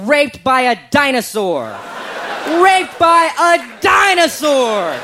Category: Comedians   Right: Both Personal and Commercial
Tags: aziz ansari aziz ansari comedian